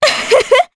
Juno-Vox-Laugh_jp.wav